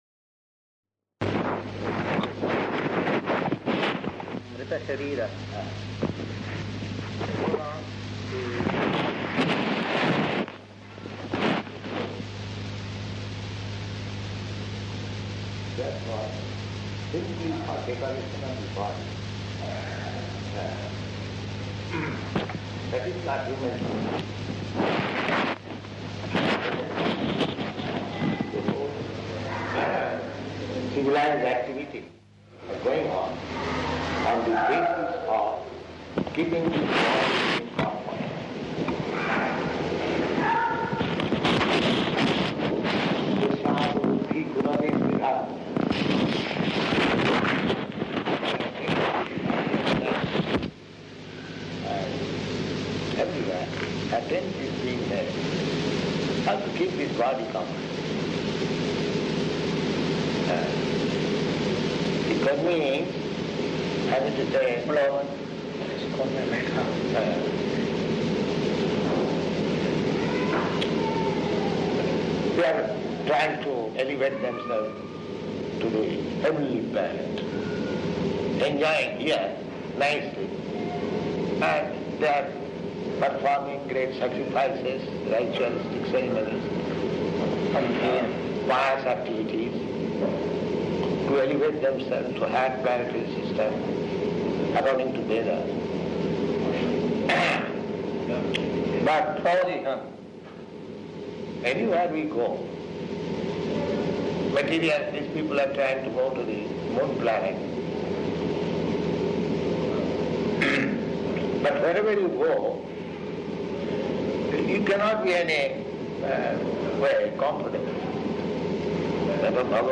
Type: Conversation
Location: Delhi